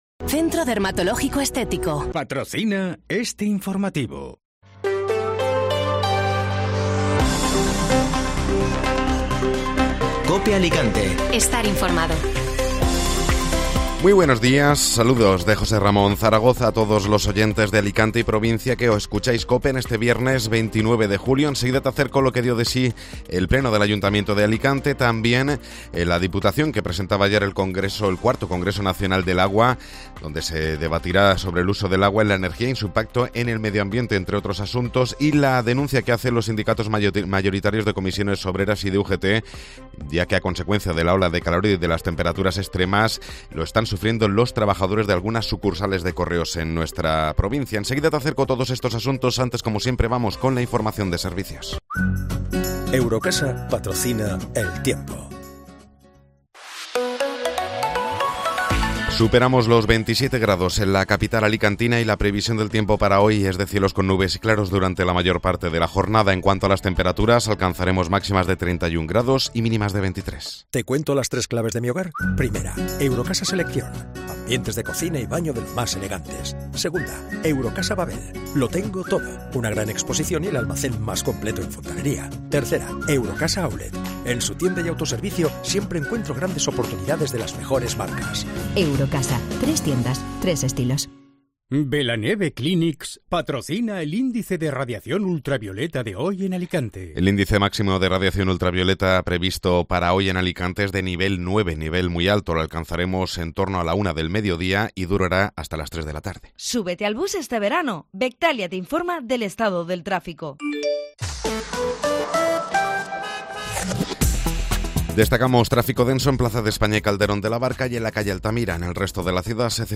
Informativo Matinal (Viernes 29 de Julio)